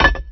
metalLightOnStone_end.WAV